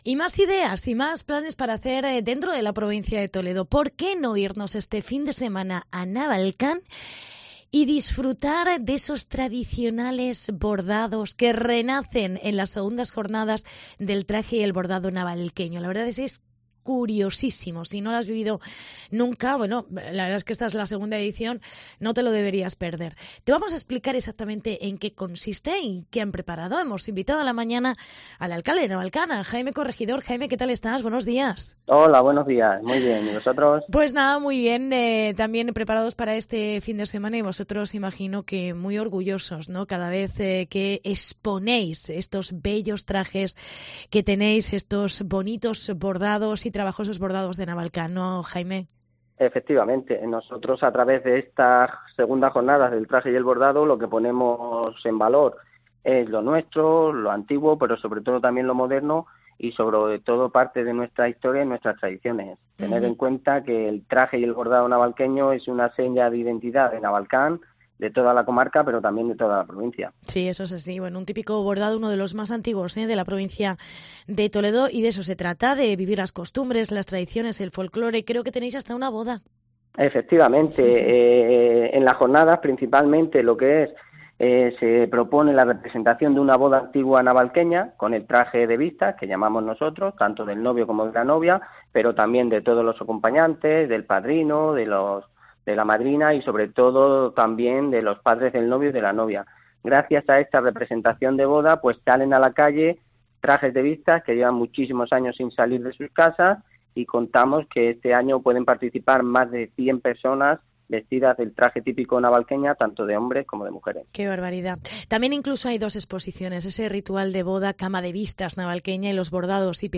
Entrevista con el alcalde de Navalcán. Jaime Corregidor